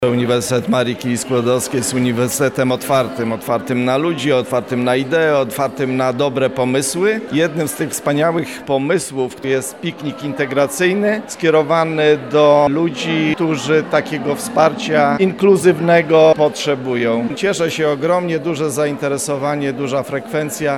mówił Rektor UMCS, Radosław Dobrowolski
Radoslaw-Dobrowolski-1.mp3